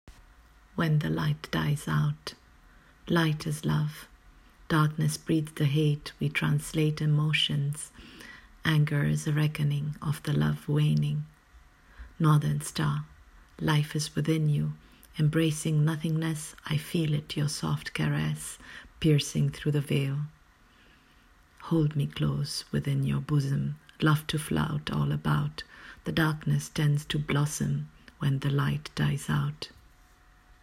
Reading of the poem: